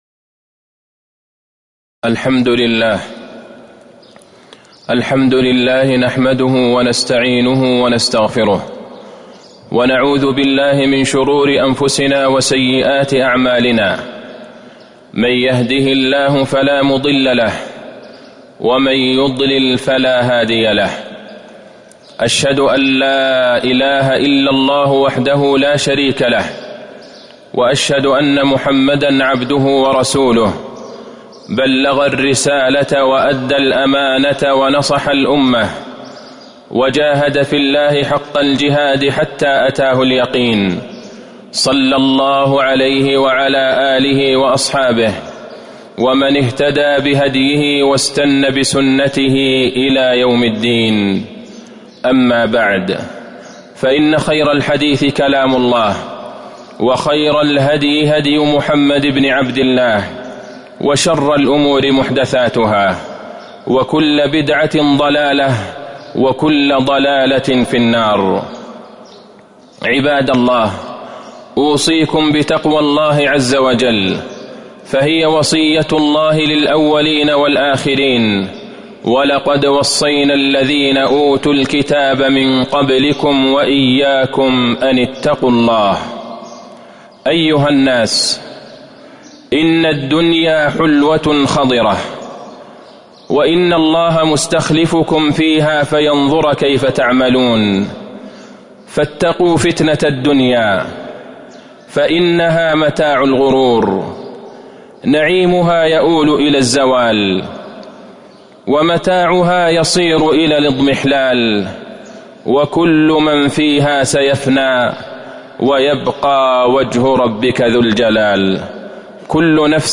تاريخ النشر ٦ جمادى الأولى ١٤٤٣ هـ المكان: المسجد النبوي الشيخ: فضيلة الشيخ د. عبدالله بن عبدالرحمن البعيجان فضيلة الشيخ د. عبدالله بن عبدالرحمن البعيجان حرمة المال العام